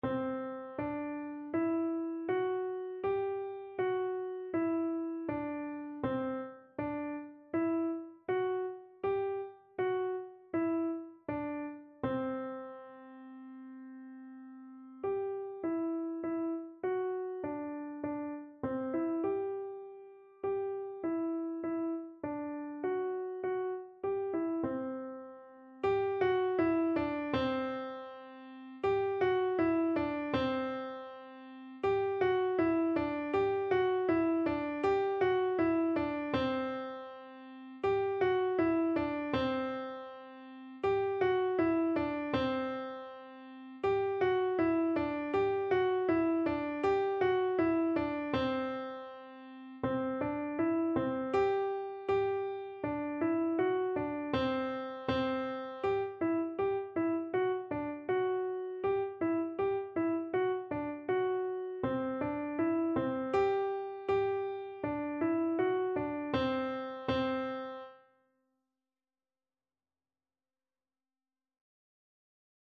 4/4 (View more 4/4 Music)
Piano  (View more Beginners Piano Music)
Classical (View more Classical Piano Music)